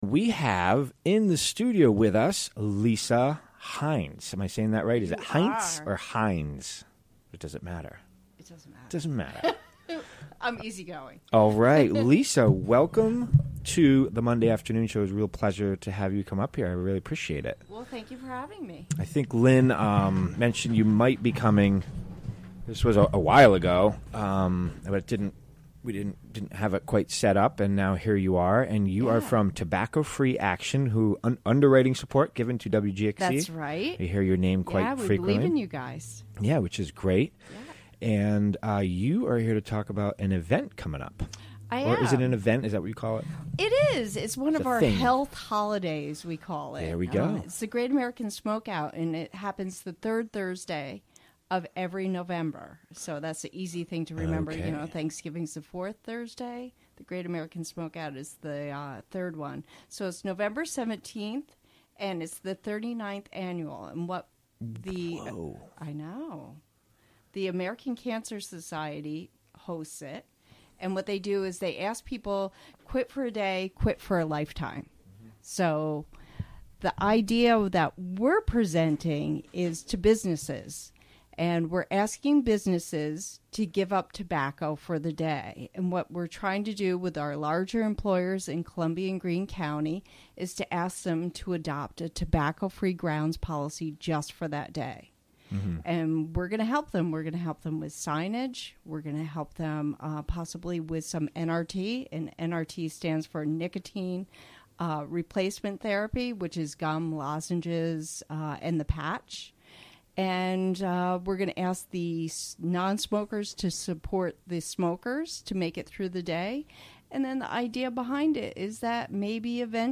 Recorded during the WGXC Afternoon Show Monday, October 17, 2016.